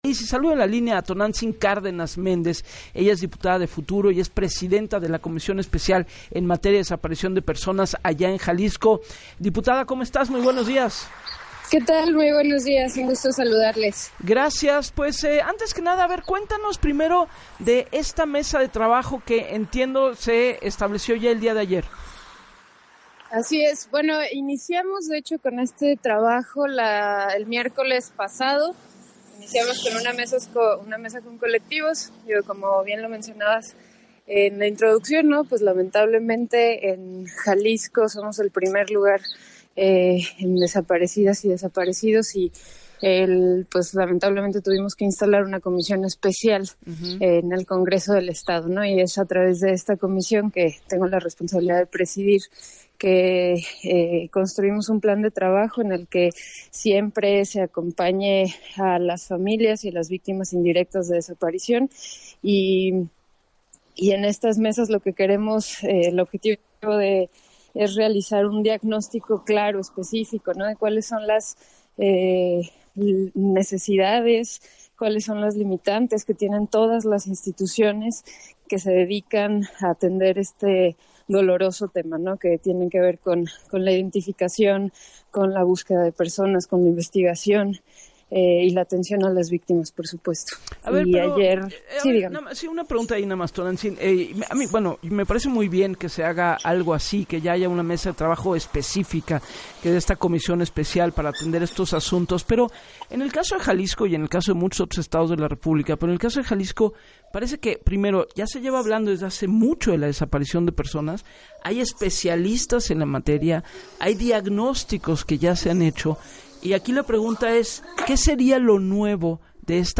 En entrevista para “Así las Cosas” con Gabriela Warkentin, la diputada de Futuro, lamentó que Jalisco ocupe el primer lugar en desapariciones, por lo cual, el miércoles pasado encabezó la primera mesa de trabajo de la Comisión con los colectivos de búsqueda, en la que dijo, “se construyó un plan de trabajo para que siempre se acompañe a las familias y a las víctimas indirectas de desaparición”.